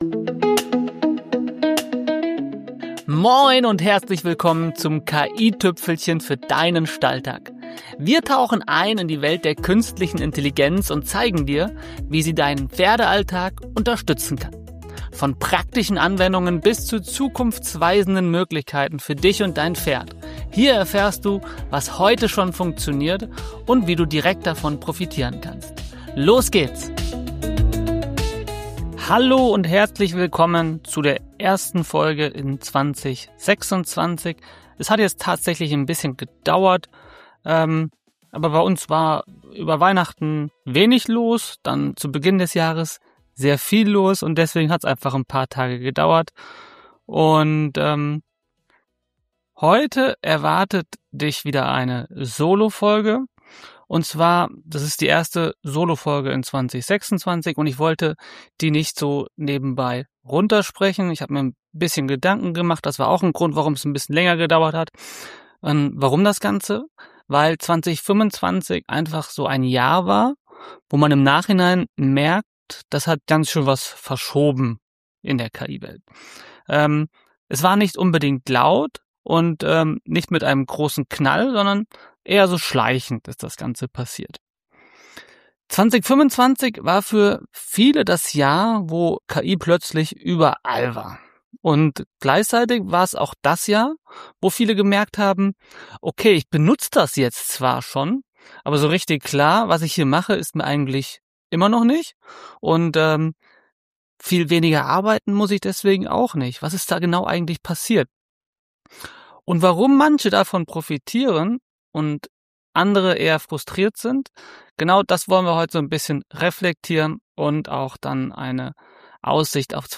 In dieser ersten Solo-Folge 2026 sortiere ich genau das ein. Warum sich KI für viele gleichzeitig allgegenwärtig und trotzdem frustrierend angefühlt hat.